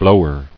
[blow·er]